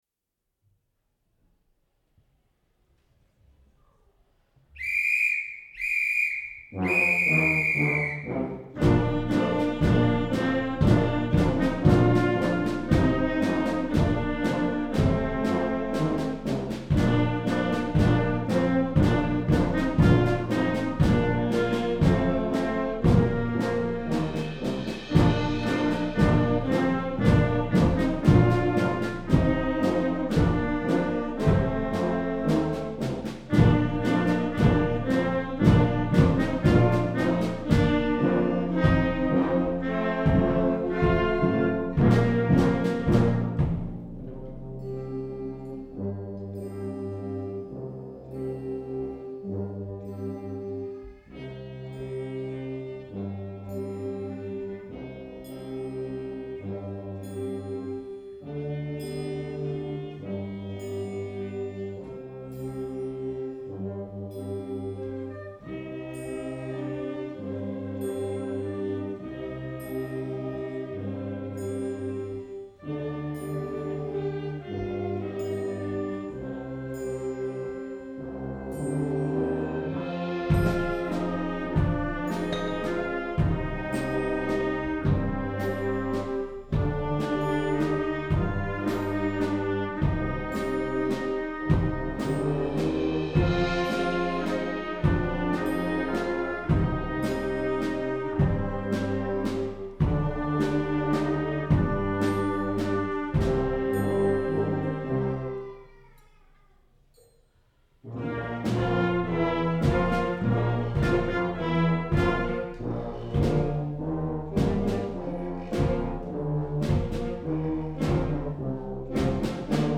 Rabenstein / Pielach
Junior-Band